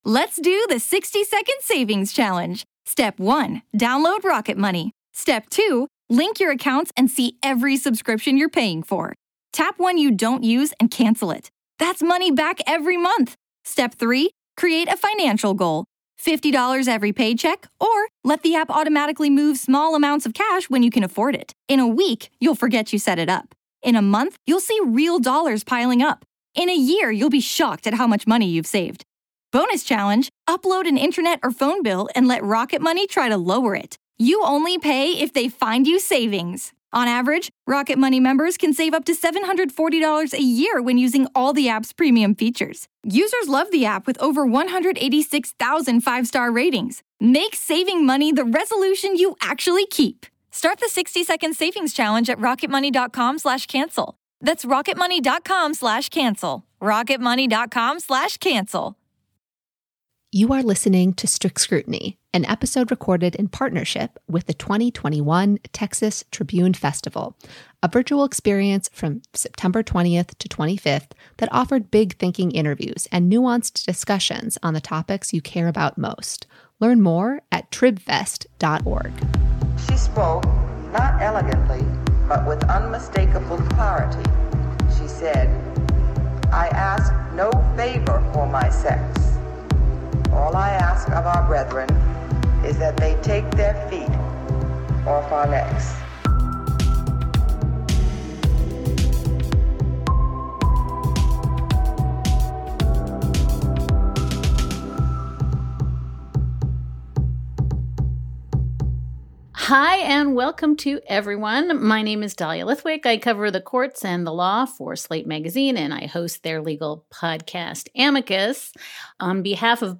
Recorded in partnership with the 2021 Texas Tribune festival, Dahlia Lithwick joins us to discuss the U.S. Supreme Court’s busy summer and do a lightening-round preview of 2.5 cases on the docket for the Court’s upcoming term.